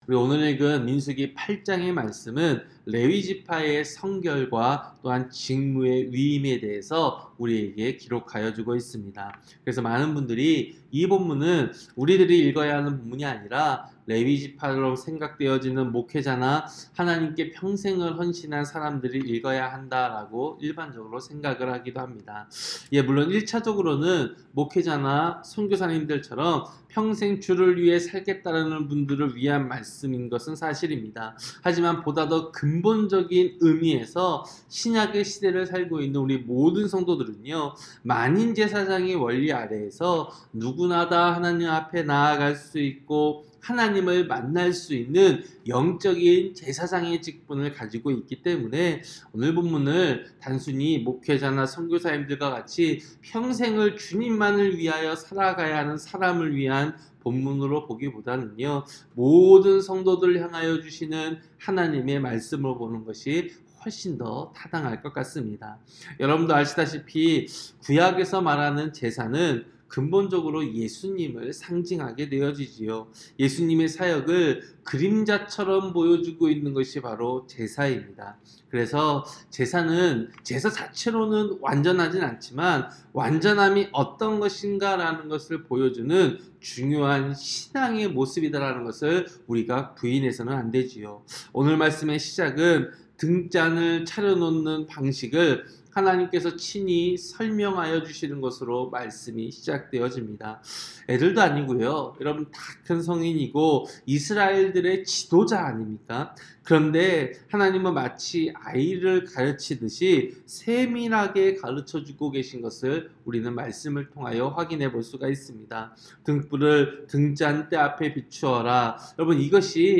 새벽설교-민수기 8장